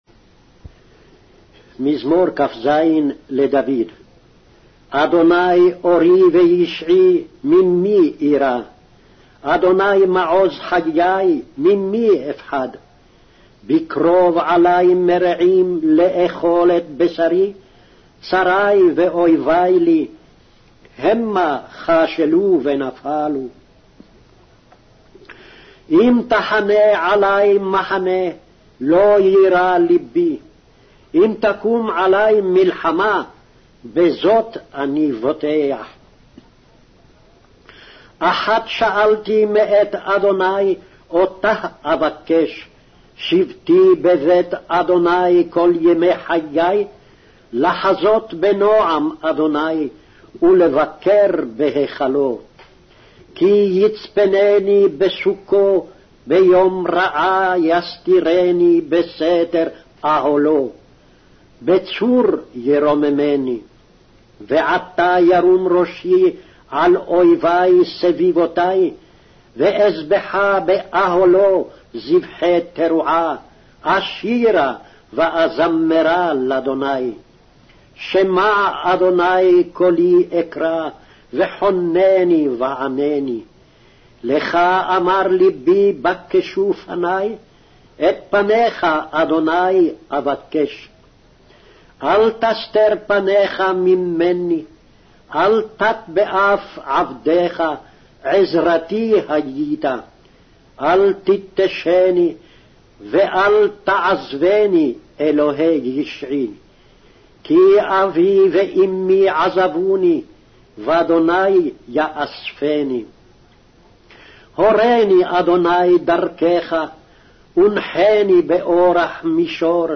Hebrew Audio Bible - Psalms 19 in Esv bible version